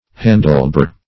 handlebar \han"dle*bar`\ n.